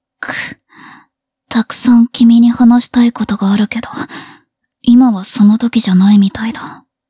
互动-厌恶的反馈.wav